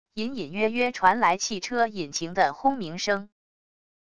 隐隐约约传来汽车引擎的轰鸣声wav音频